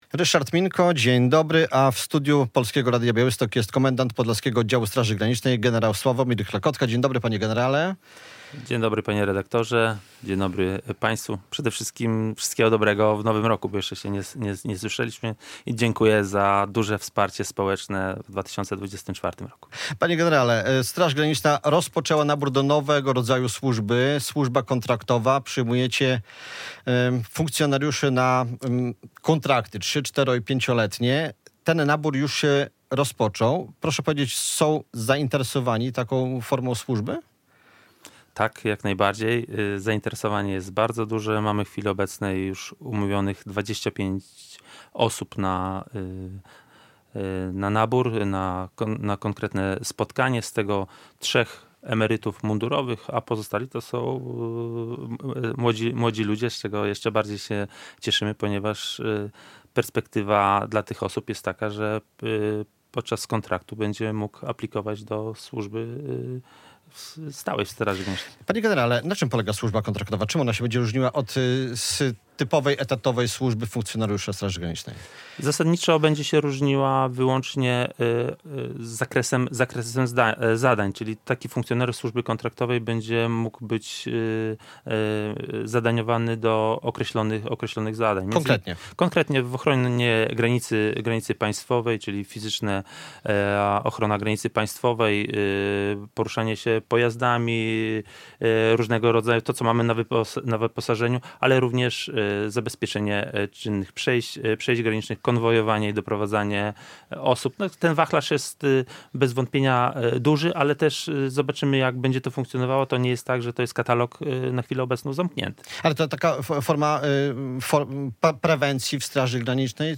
Radio Białystok | Gość | Sławomir Klekotka - komendant Podlaskiego Oddziału Straży Granicznej
O służbie kontraktowej oraz aktualnej sytuacji na polsko-białoruskiej granicy mówił w Rozmowie Dnia komendant Podlaskiego Oddziału Straży Granicznej gen. Sławomir Klekotka.